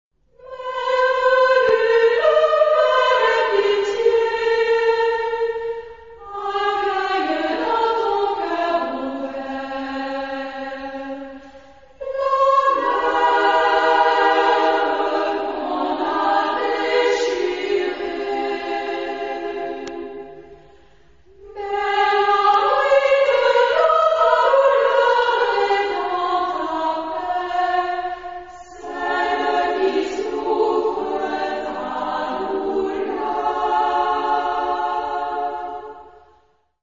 Genre-Style-Forme : Cantate ; Profane ; contemporain
Type de choeur : SAA OU SSA  (3 voix égales de femmes )